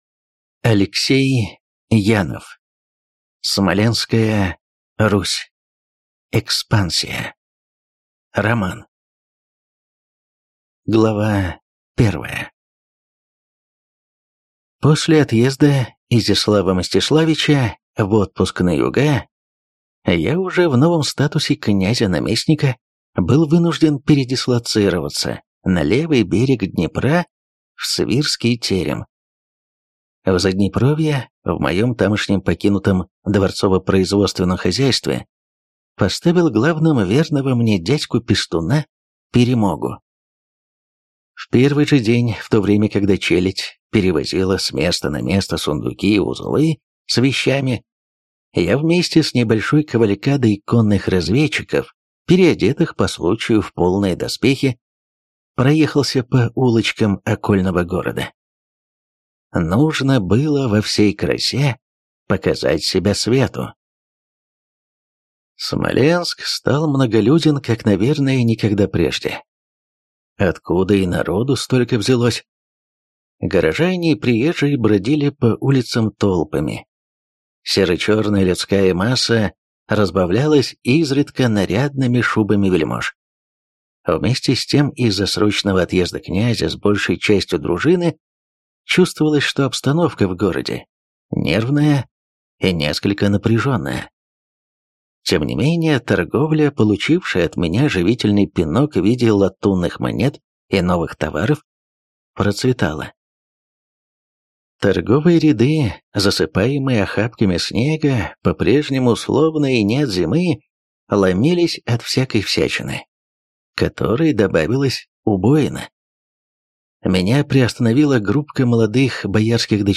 Аудиокнига Смоленская Русь. Экспансия | Библиотека аудиокниг